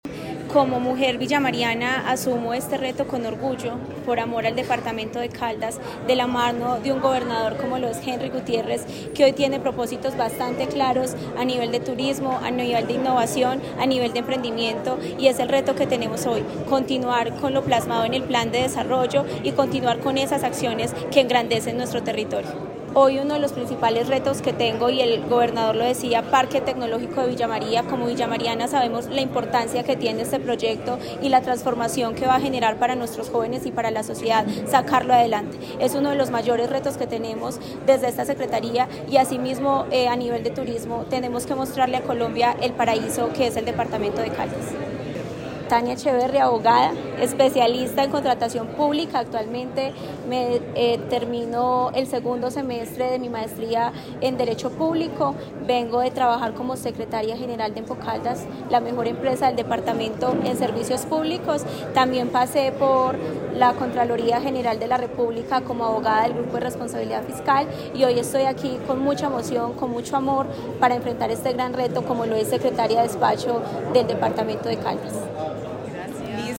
Tania Echeverry Rivera, secretaria de Desarrollo, Empleo e Innovación de Caldas
Tania-Echeverry-Rivera-Secretaria-de-Desarrollo-Empleo-e-Innovacion-de-Caldas.mp3